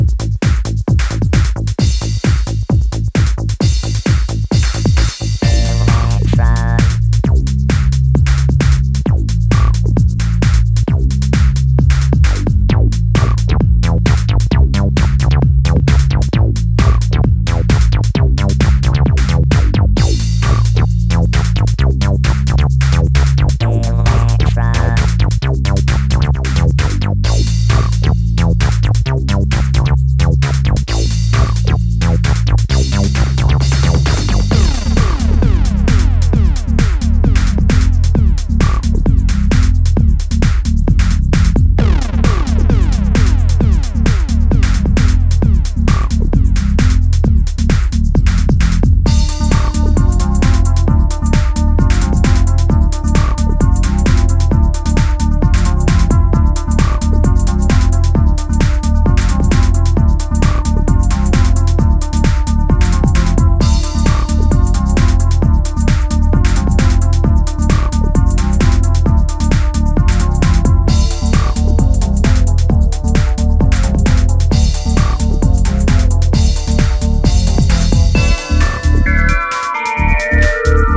the e.p is full of timeless, heavy, dancefloor rhythms.